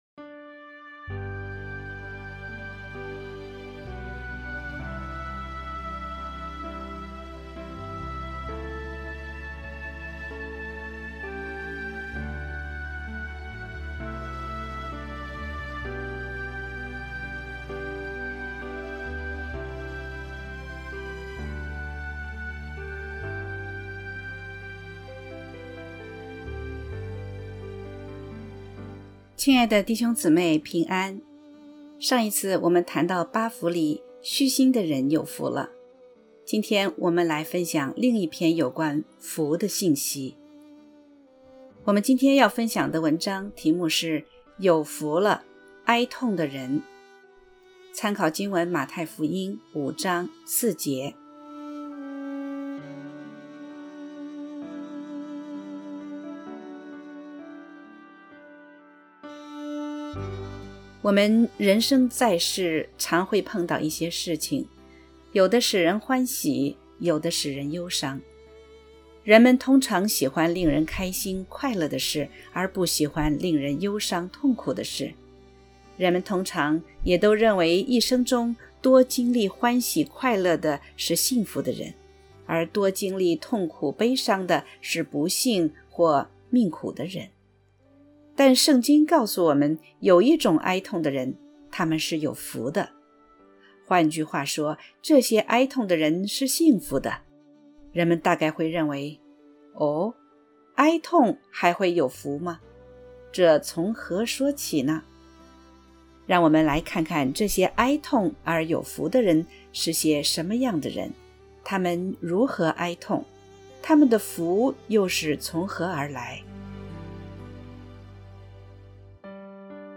（合成）A哀恸的人有福了R.mp3